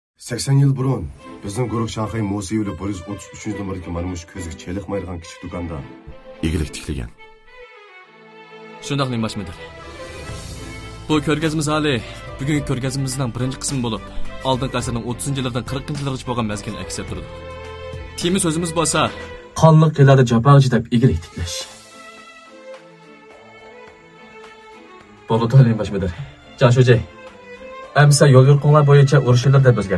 磁性魅力